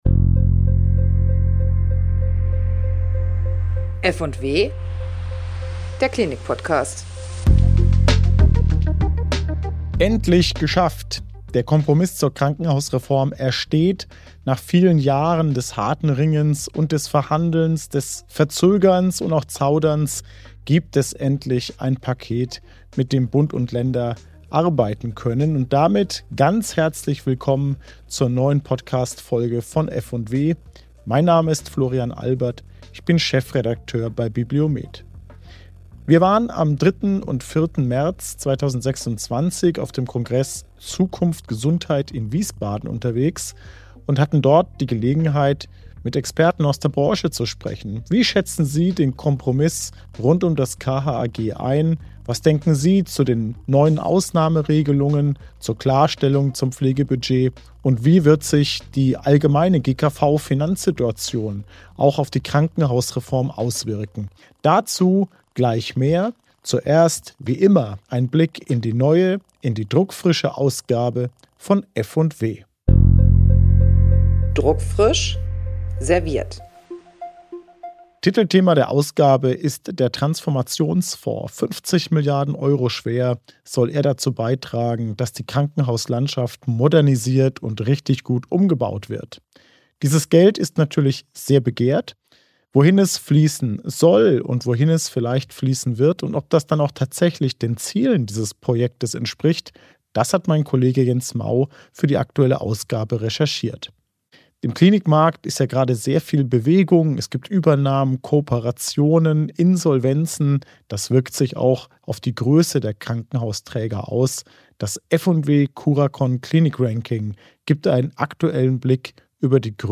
Beim Kongress Zukunft Gesundheit in Wiesbaden sprachen wir mit Verantwortlichen aus Kliniken, Krankenkassen und Pflege über Chancen und Brüche dieser Einigung. Sie bewerten die neuen Ausnahmeregelungen, die Anpassungen am Transformationsfonds und die Klarstellung zum Pflegebudget. Die Folge zeigt, wie Mediziner, Geschäftsführer, Pflegeleitungen und Kassenvertreter die Reform einschätzen, welche Konflikte bleiben und warum viele Fragen zur Finanzierung der gesetzlichen Krankenversicherung weit offen sind.